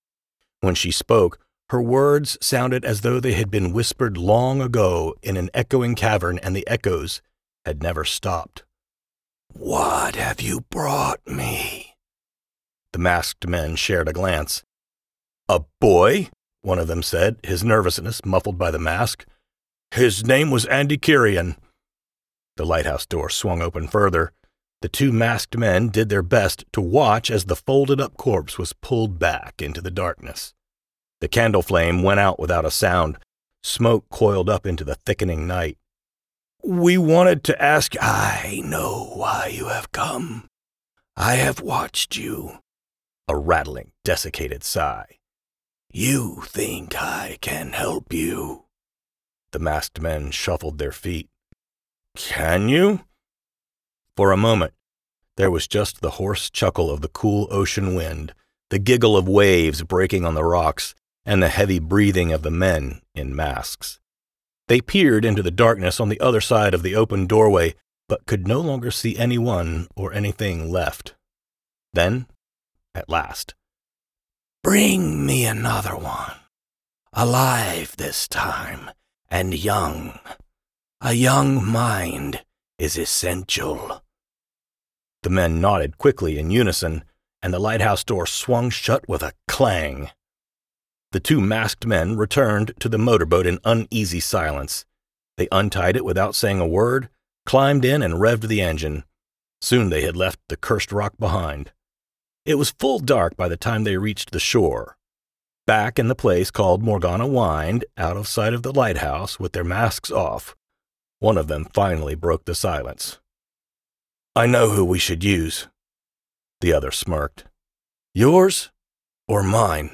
Audiobook Sample
Specializing in audiobook narration and animated character work, I bring a warm, rich baritone with a wide character range — from wide-eyed children and creatures of every size and shape, to gruff military veterans.